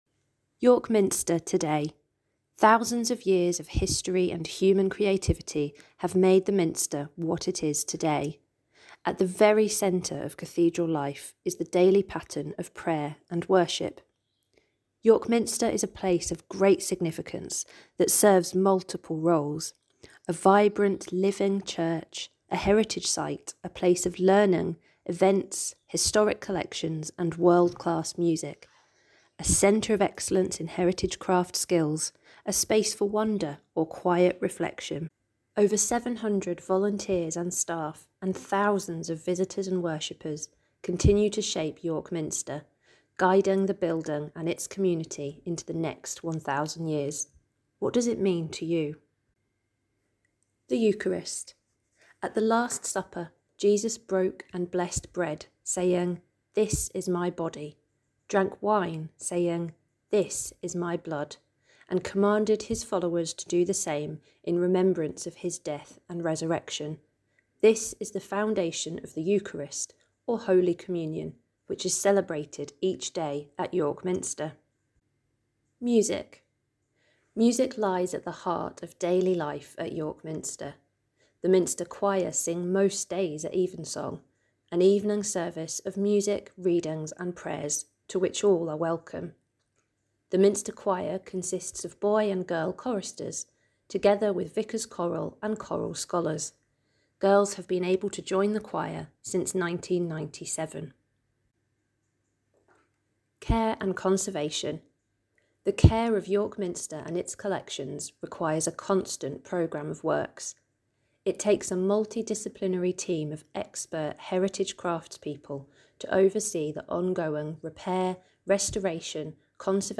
To listen to the audio description of the museum, please look for the squares with a number in next to various parts of the exhibition, and press play on the correlating number below.